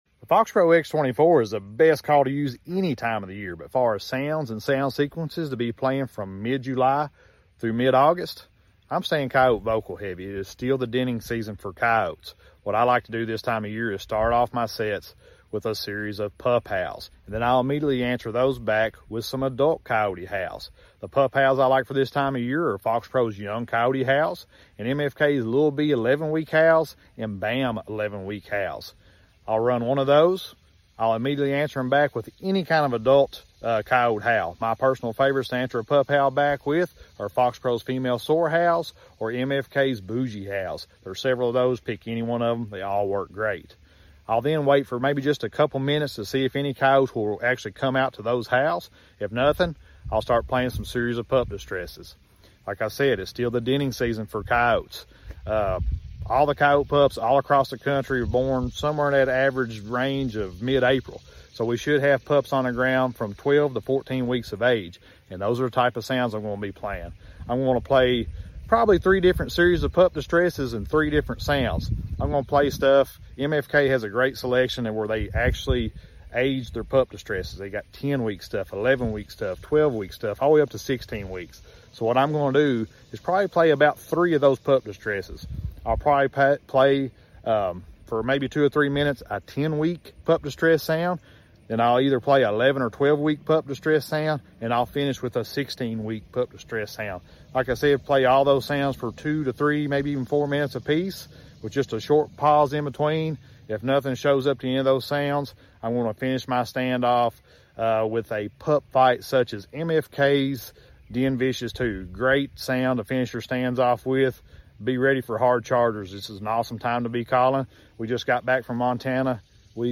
for mid July through mid August coyote calling.